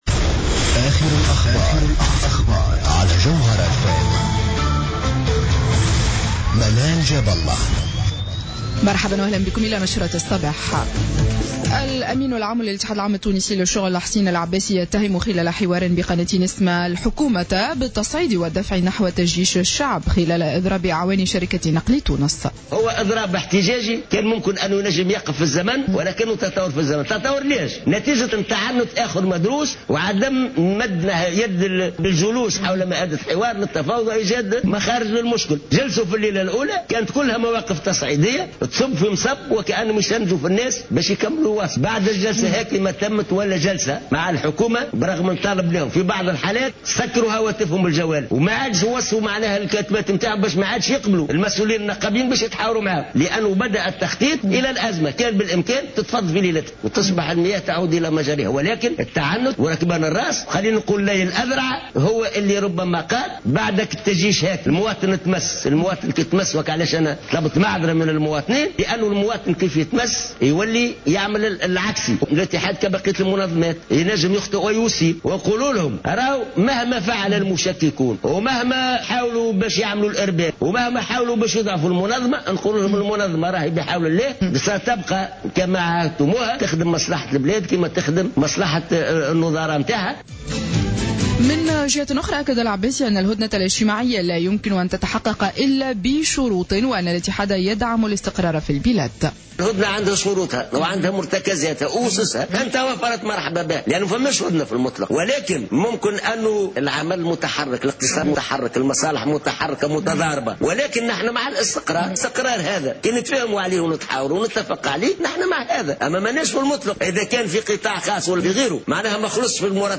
نشرة أخبار السابعة صباحا ليوم السبت 17 جانفي 2014